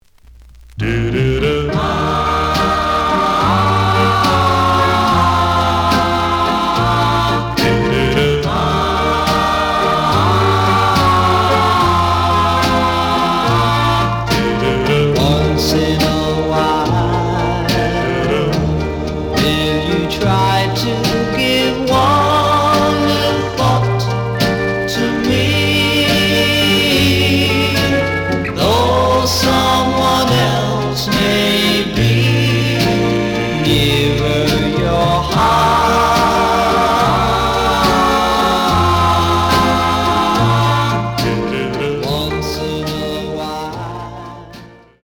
試聴は実際のレコードから録音しています。
●Genre: Rhythm And Blues / Rock 'n' Roll
●Record Grading: EX- (盤に若干の歪み。多少の傷はあるが、おおむね良好。)